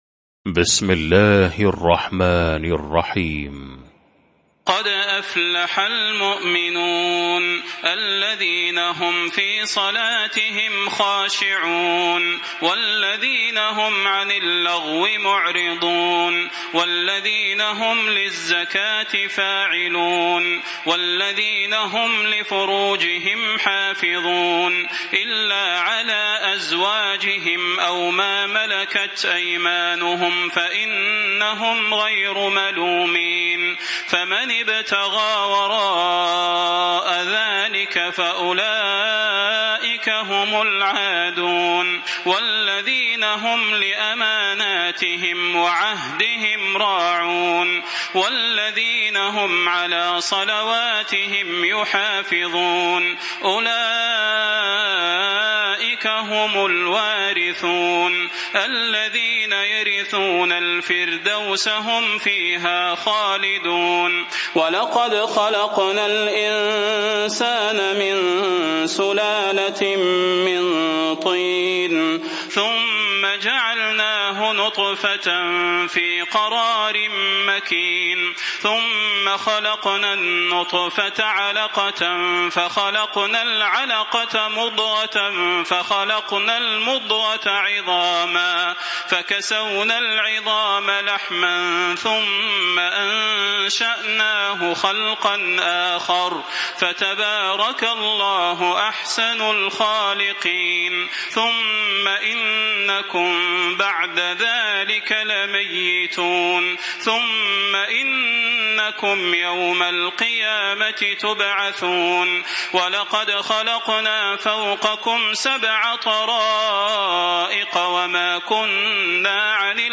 المكان: المسجد النبوي الشيخ: فضيلة الشيخ د. صلاح بن محمد البدير فضيلة الشيخ د. صلاح بن محمد البدير المؤمنون